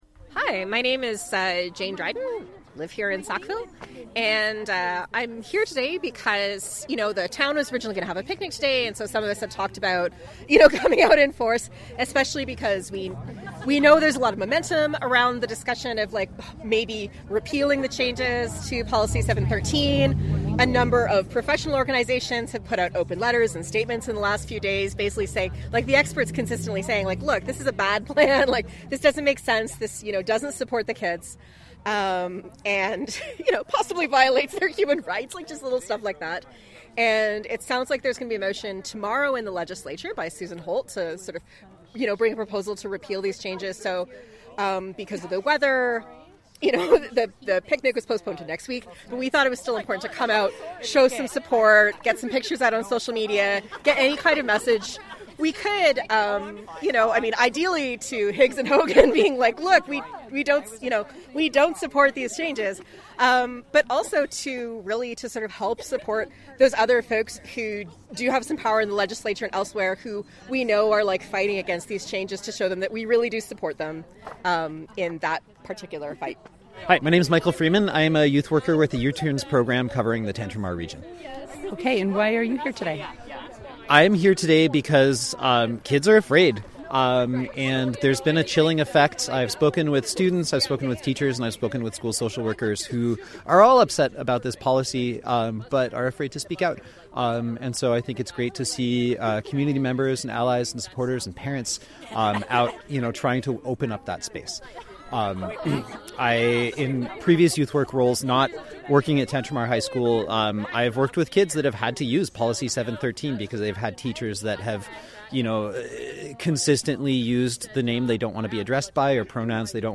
On Wednesday, a group of about 18 Sackville residents gathered to voice their support for a repeal of the changes.
CHMA spoke to some of parents, teachers, students and others gathered to find out why they showed up: